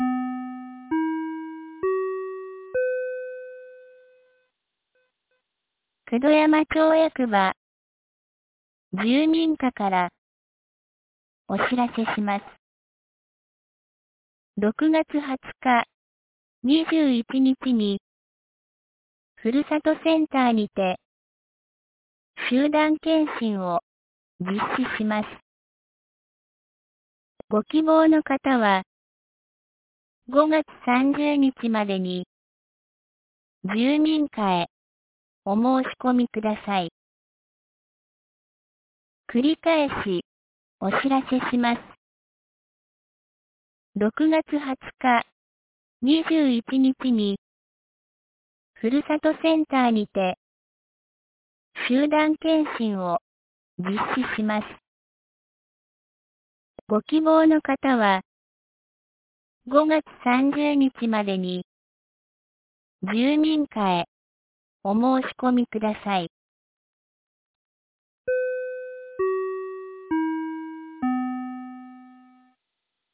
2025年05月27日 15時01分に、九度山町より全地区へ放送がありました。